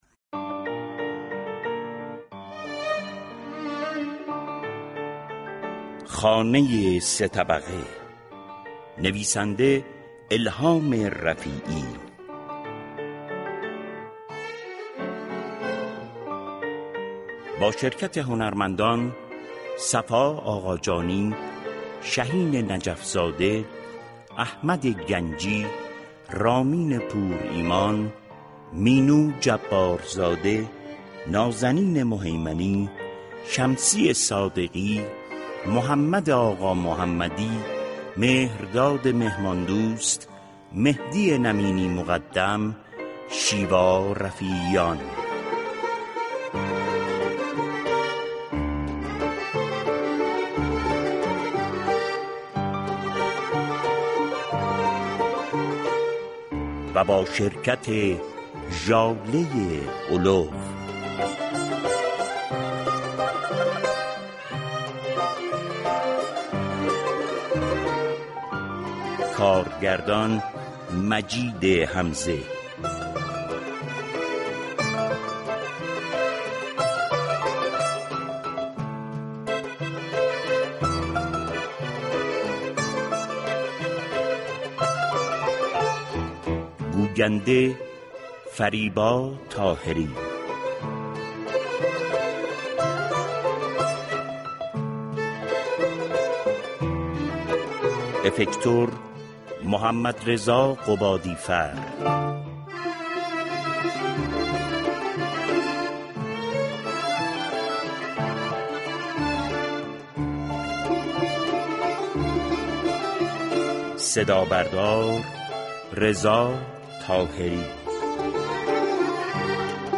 جمعه 18 آبان ماه ، شنونده سریال رادیویی